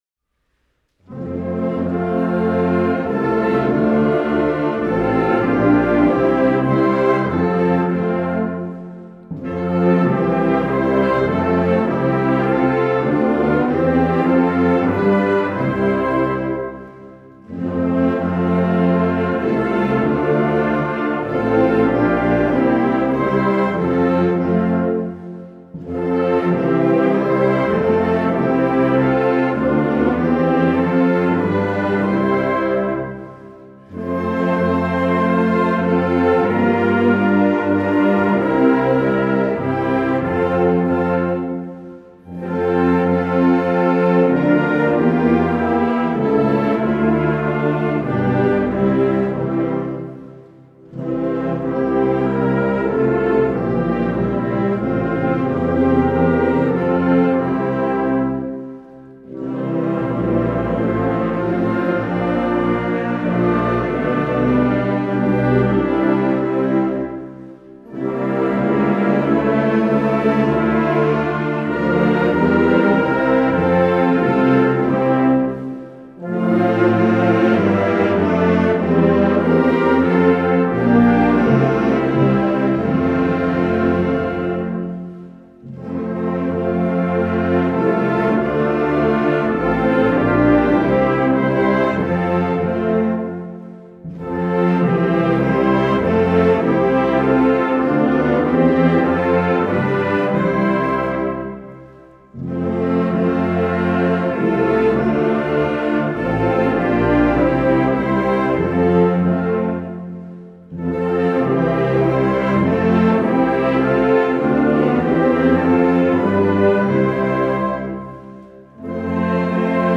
„Munter und angenehm“ notierte Knecht und gab damit auch Interpretationshinweise, wie das Lied gesungen werden sollte.
Melodie ohne Gesang